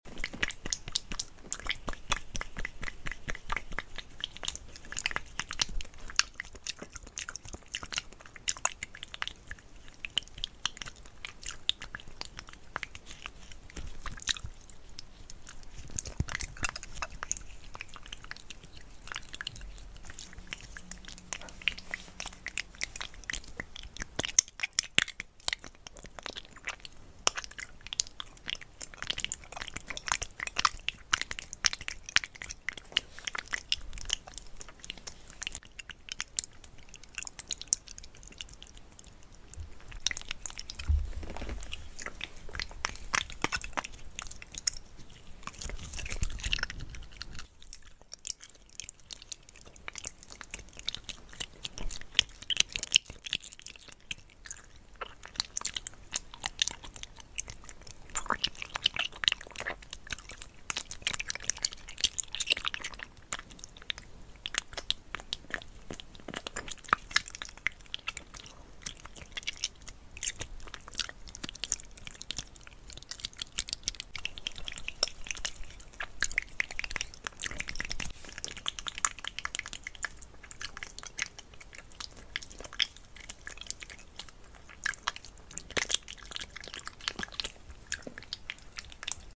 Asmr | Spit Painting